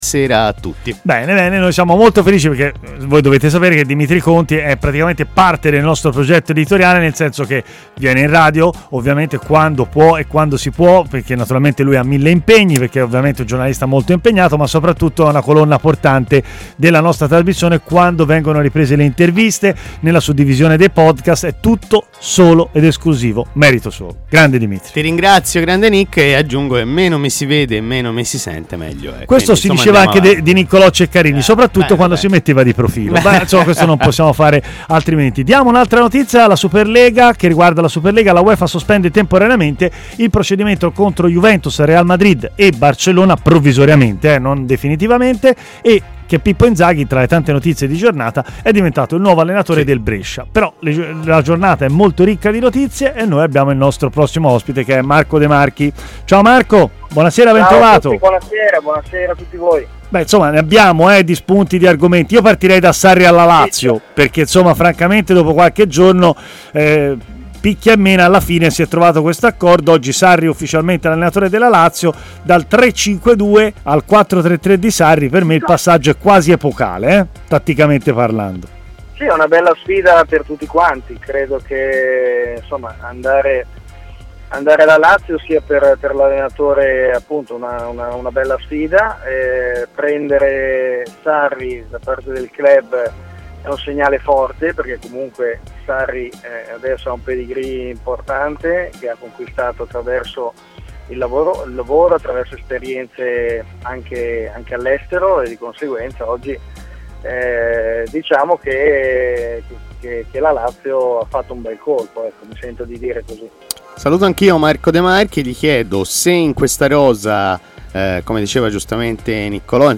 in diretta a Stadio Aperto su TMW Radio